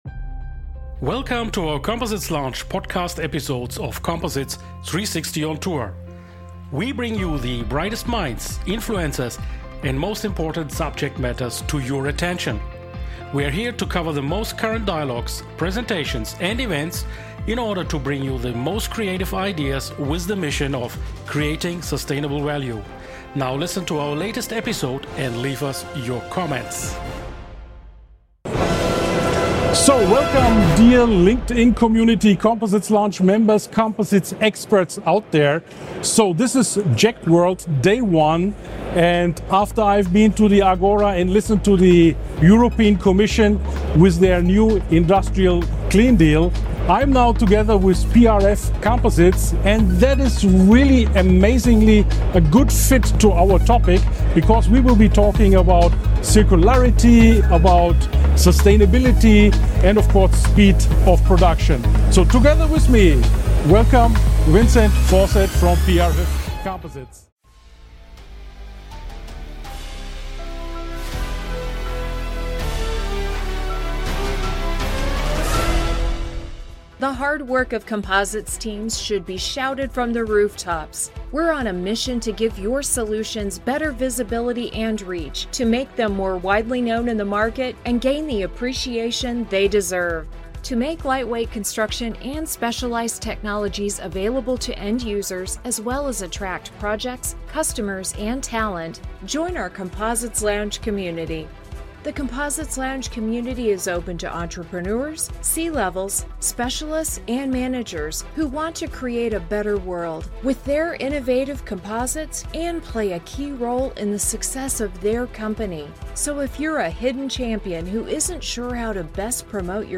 Imagine a world where cutting-edge technology meets sustainability— PRF Composite Materials is making it a reality. Welcome to Composites Lounge's first stop at JEC World 2025!